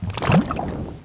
c_slime_no.wav